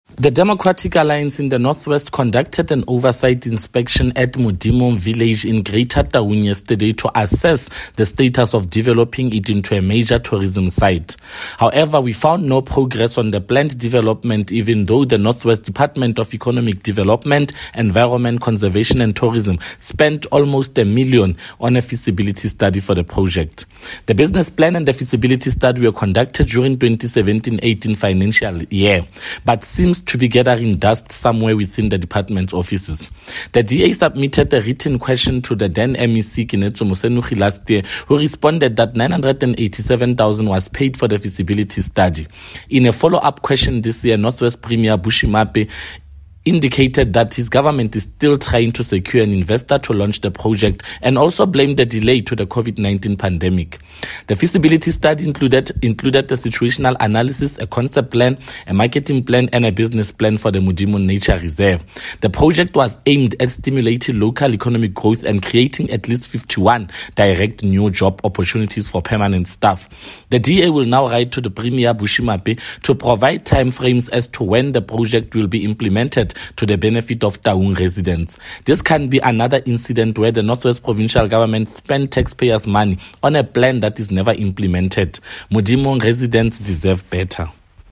Note to Broadcasters: Please find a linked soundbite in
English by Freddy Sonakile MPL.